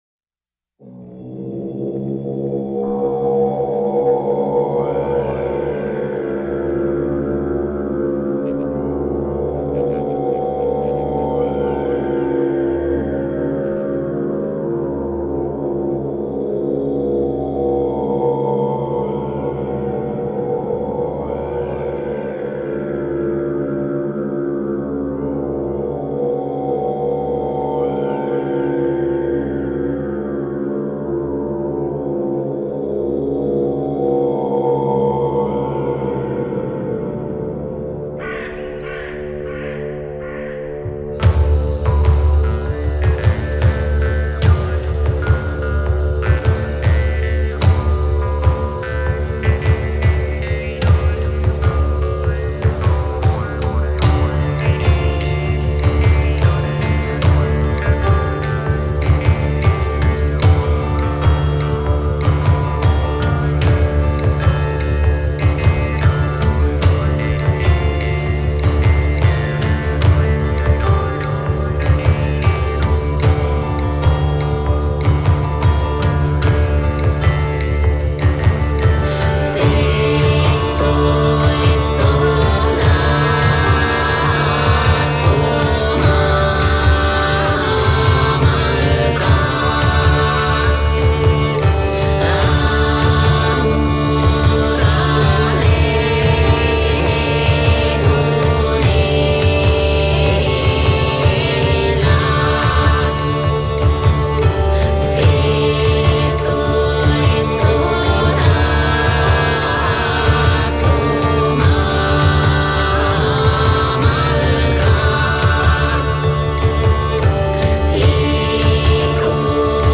diesmal jedenfalls gothic -
mit folk- und MA-tendenzen.
anfangs die männlichen stimmen. wie das heilige OM.
dann der frauenchor (vll digital vervielfältigt).